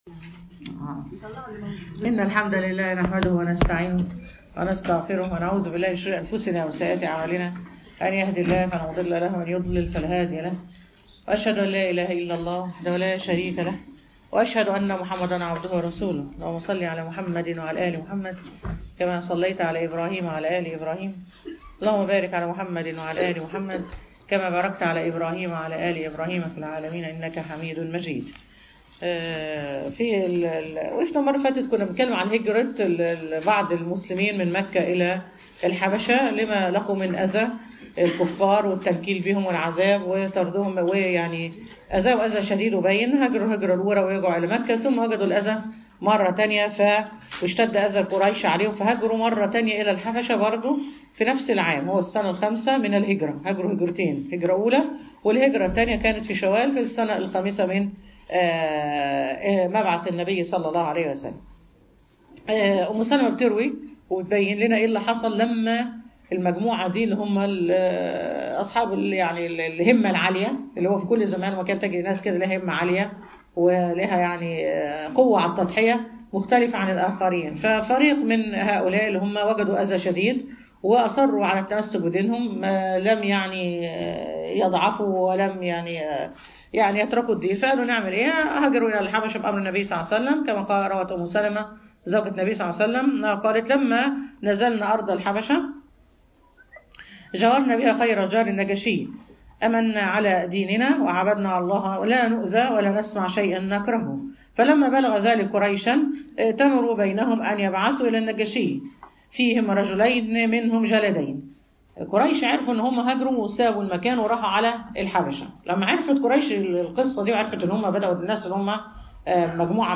سيرة النبي ﷺ_المحاضرة الثانية عشر